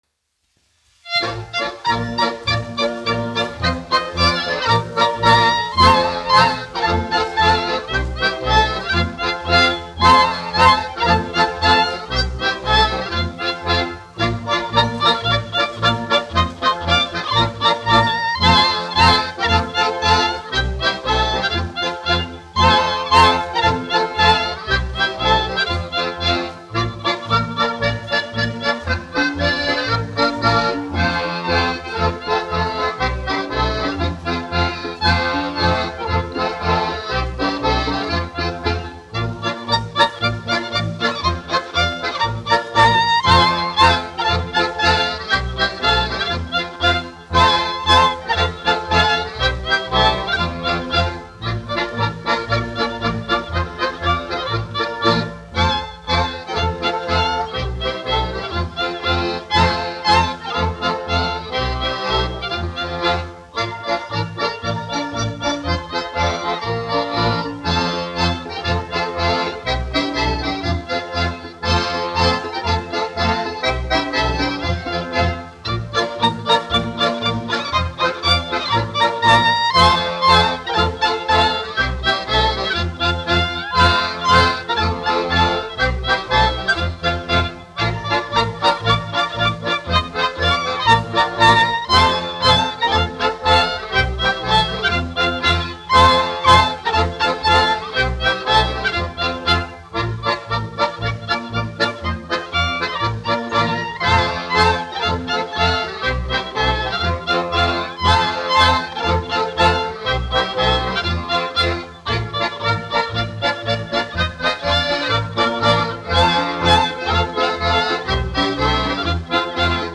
Alfrēda Vintera Jautrā kapela (mūzikas grupa), izpildītājs
1 skpl. : analogs, 78 apgr/min, mono ; 25 cm
Latviešu tautas dejas